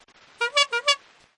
Tesla Lock Sound Horn – Toy
Horn Toy sound
(This is a lofi preview version. The downloadable version will be in full quality)
JM_Tesla_Lock-Sound_Horn-Toy_Watermark.mp3